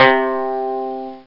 Banjo Lo Sound Effect
Download a high-quality banjo lo sound effect.
banjo-lo.mp3